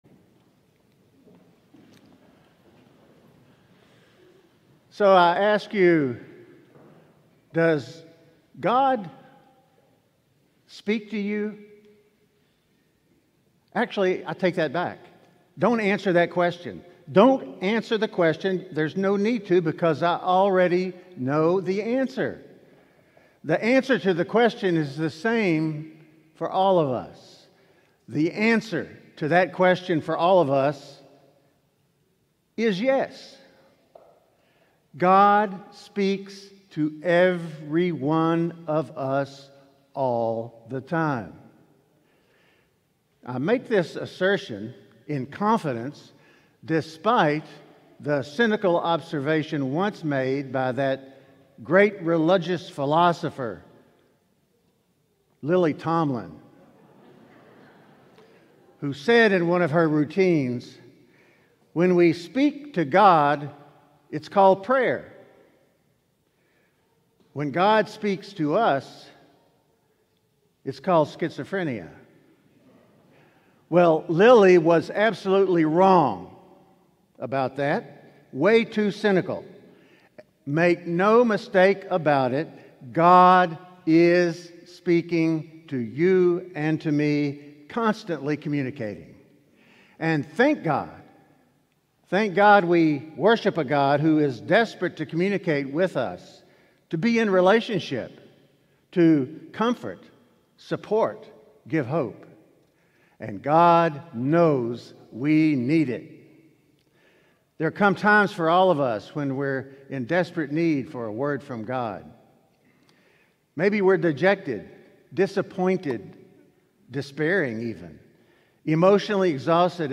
Sermon: Does God Speak to You?